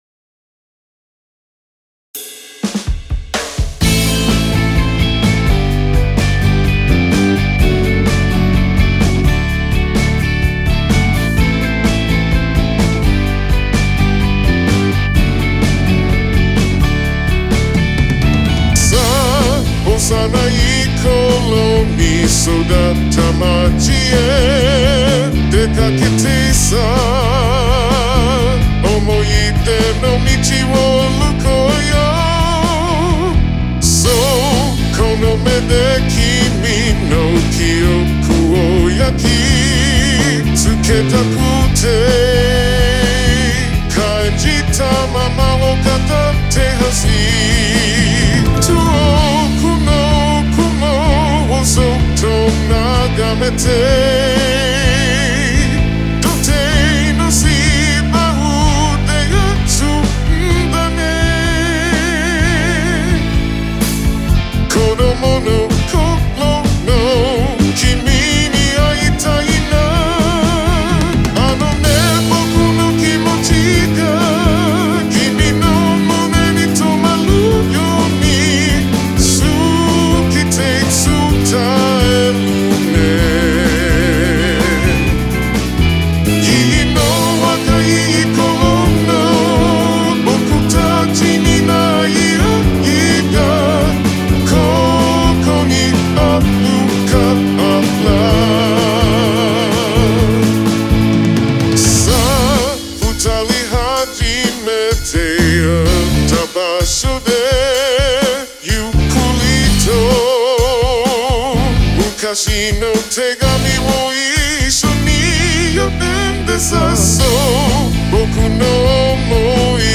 昭和感のあるポップスで、歌詞を依頼主様に用意していただきました。
作曲、編曲、Mix、マスタリングをやらせていただきました。
昭和歌謡のような雰囲気の楽曲も対応可能です。
これもAiボーカルを使用しました。